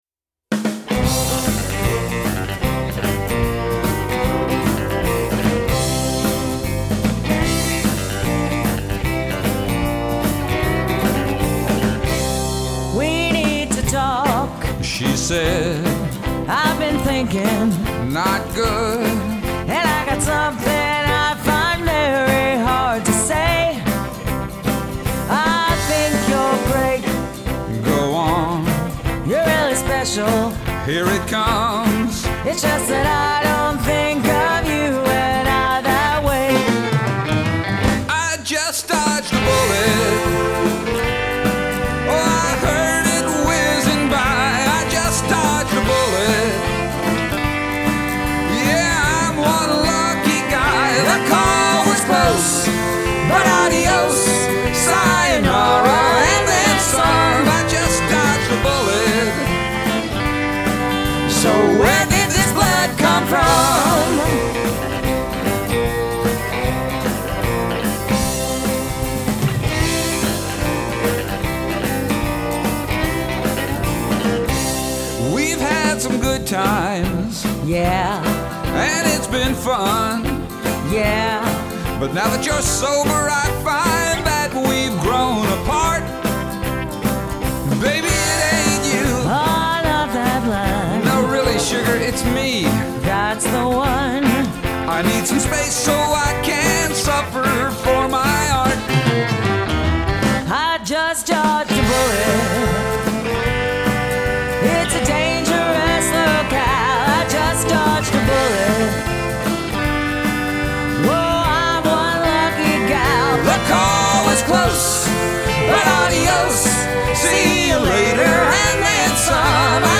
3:45 Up Trailer park operetta